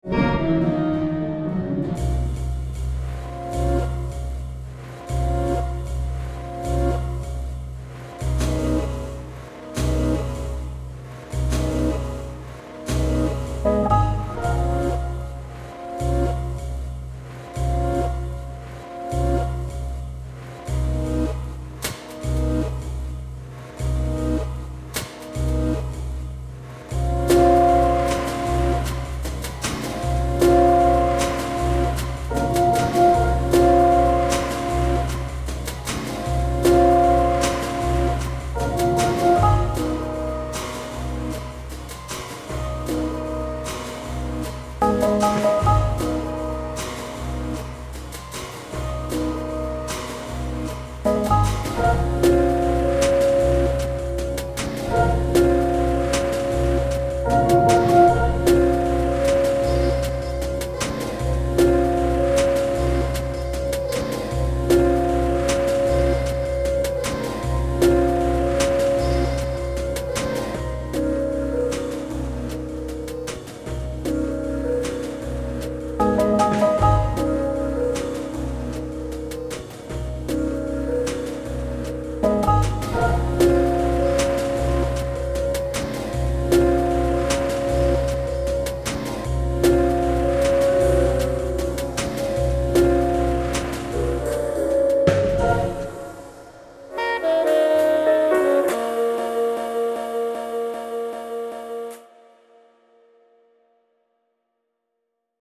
The deconstruction of the source material is well done.